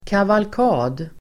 Ladda ner uttalet
Uttal: [kavalk'a:d]